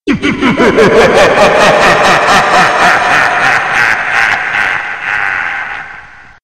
Звуки злодея
Смех злого человека, задумавшего преступление